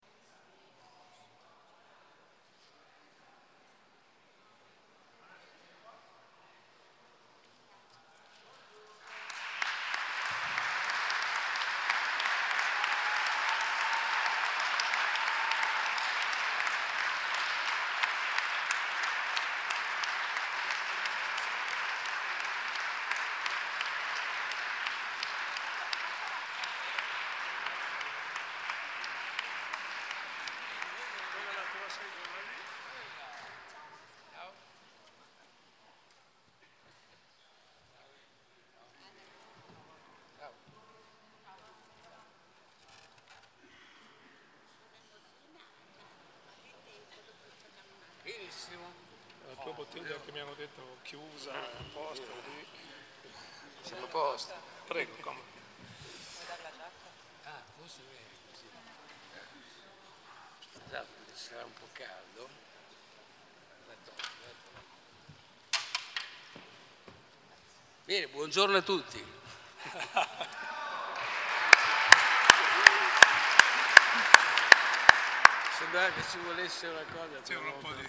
Seduta del 17/01/2018 Conferimento della Cittadinanza Onoraria a Vasco Rossi. Consegna le chiavi della città a Vasco Rossi